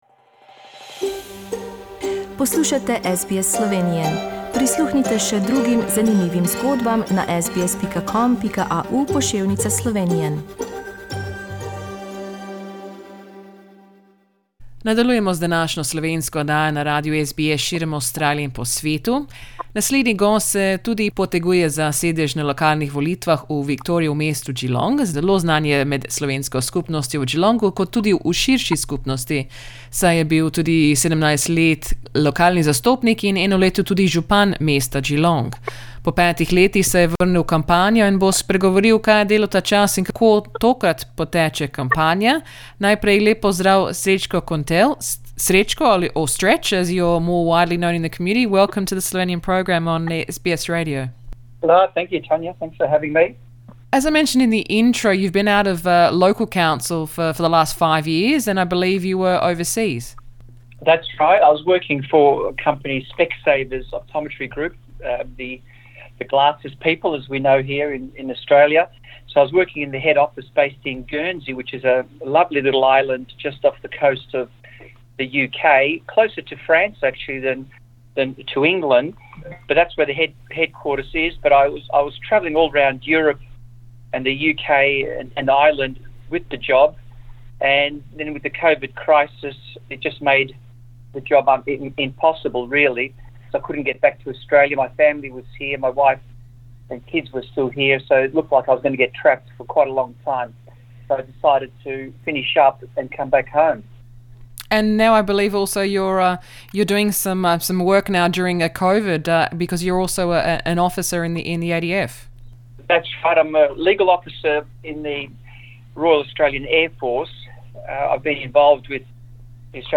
Po 5 letnem bivanju v tujini se je vrnil v Avstralijo in se poteguje na lokalnih volitvah v Viktoriji. Pogovarjali smo se o tokratni kampanji in kako trenutne razmere vplivajo nanjo.